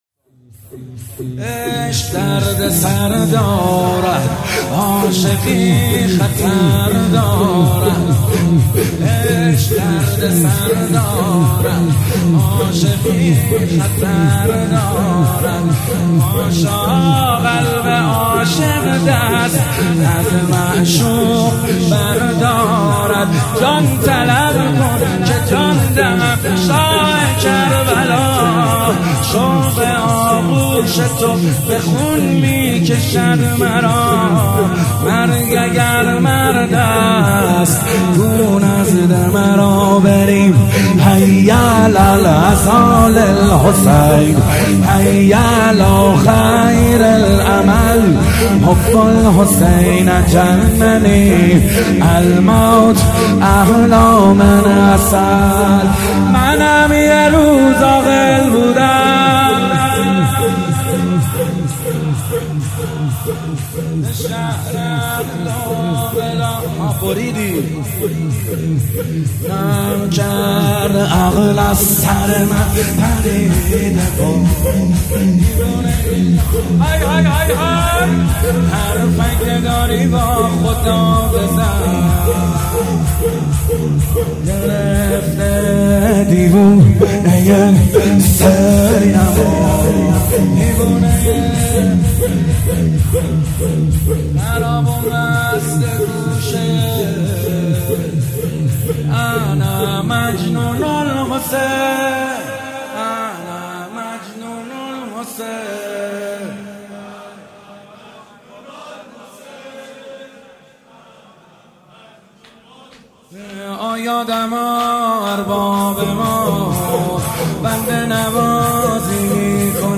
مداحی شور شب هشتم محرم 1400